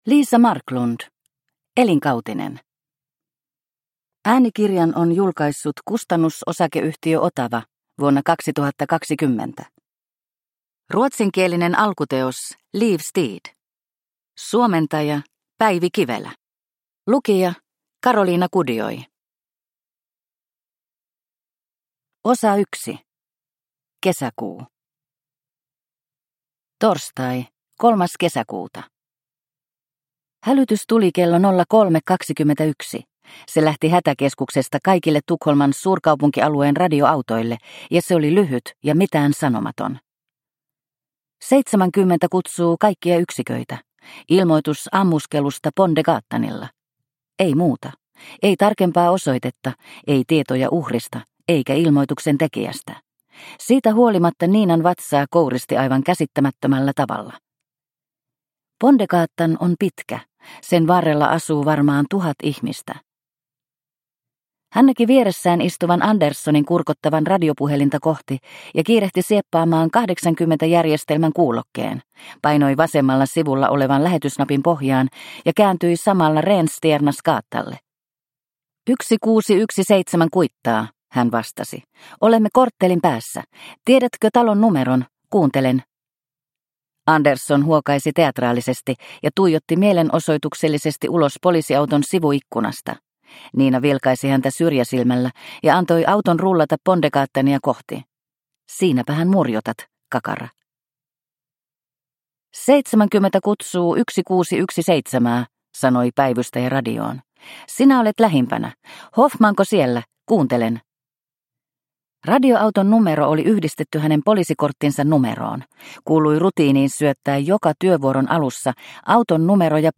Elinkautinen – Ljudbok – Laddas ner